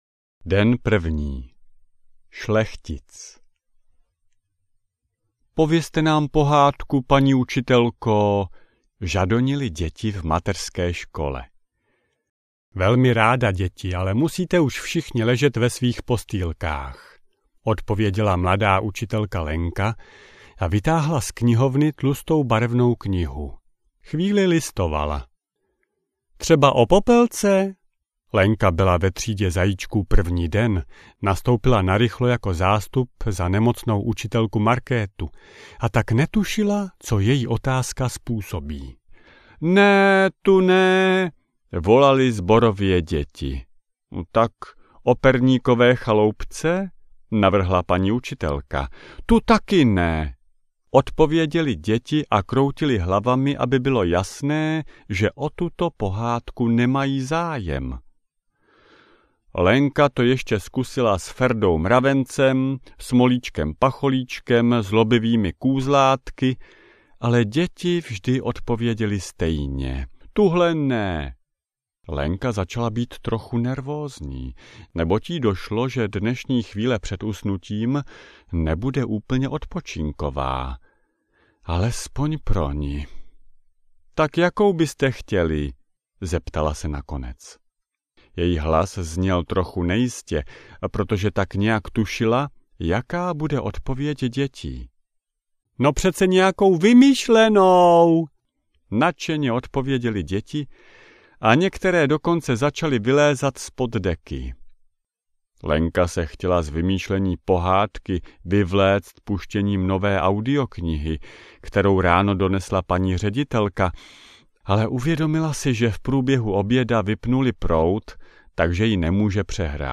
Zaječí příběhy audiokniha